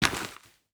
Dirt footsteps 10.wav